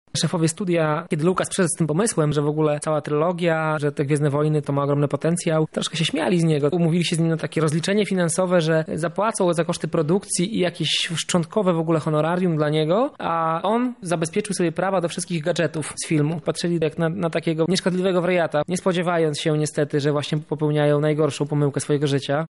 filmoznawca.